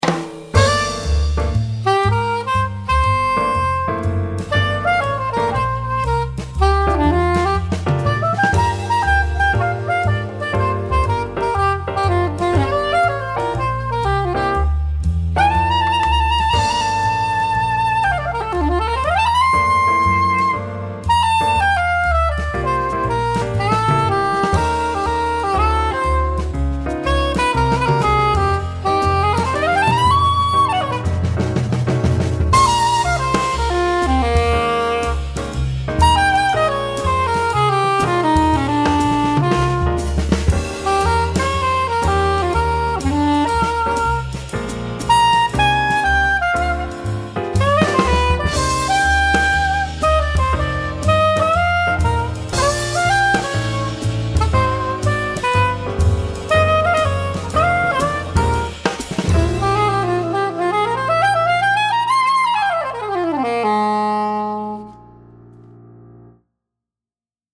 どこか懐かしく毎日吹いても飽きが来ない、サックス本来の音を目指しました
音色と特徴渋め、響きがある、吹きやすい、演奏者の好みの音が出る
スタイルジャズ
ソプラノ
VISopJazz.mp3